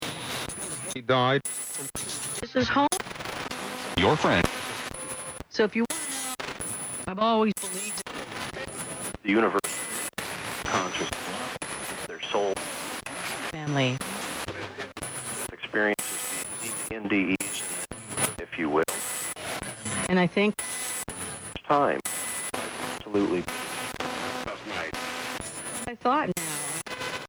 The Spirit Box swept through the otherworldly static in the atmosphere for an answer, and what we heard was eerie:
Here's the audio file from the Spirit Box: